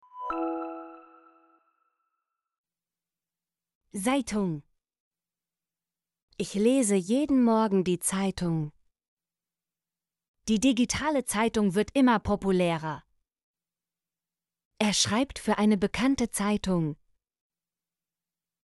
zeitung - Example Sentences & Pronunciation, German Frequency List